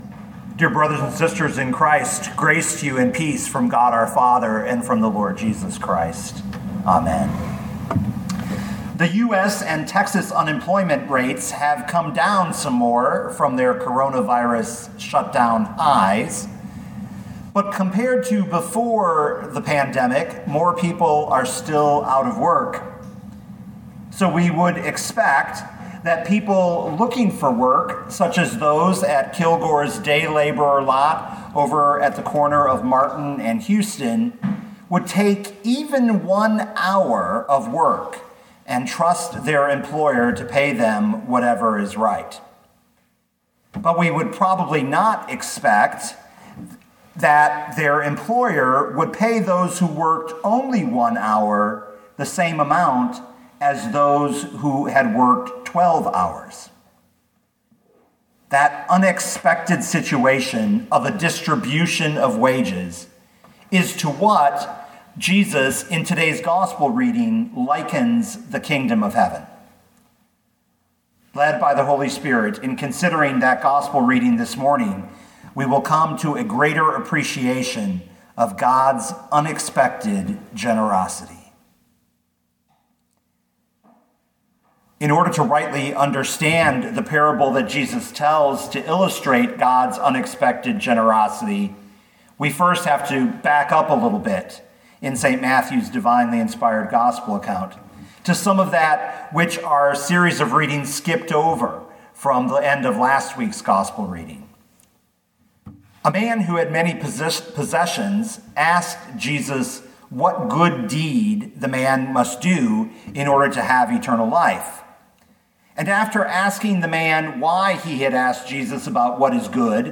2020 Matthew 20:1-16 Listen to the sermon with the player below, or, download the audio.